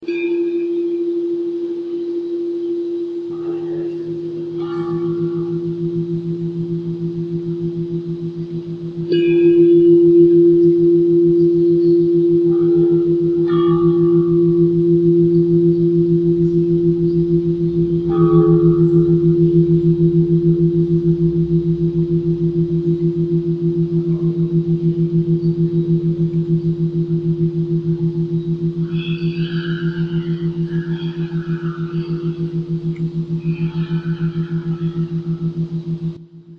Klaus Weber - Large Dark Wind Chime 2, 2008